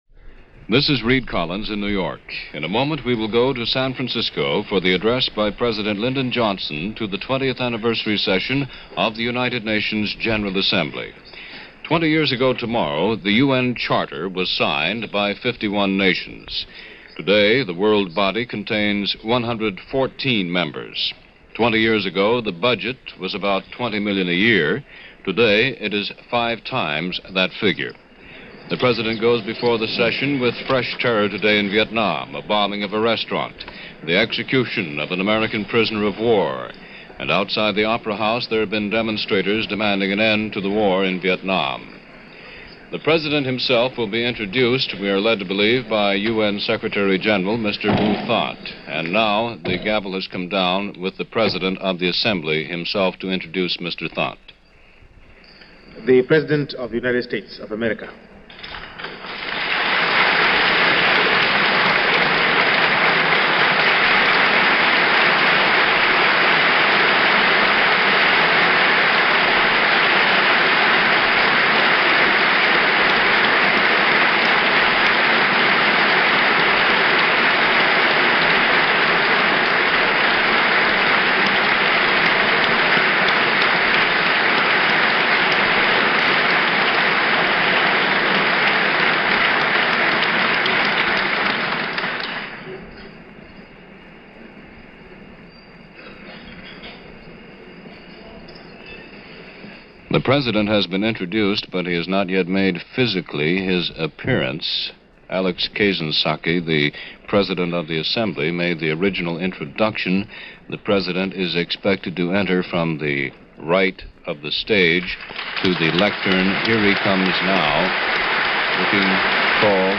Johnson At The UN - June 25, 1965 - address to the assembly on the 20th anniversary of the founding of the United Nations.
On the 20th anniversary of that historic beginning, a gathering was held in San Francisco to commemorate the 20th anniversary beginning on June 25th 1965.